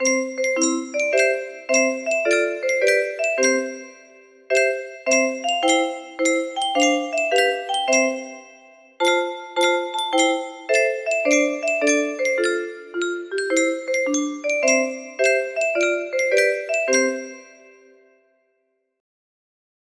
Wow! It seems like this melody can be played offline on a 15 note paper strip music box!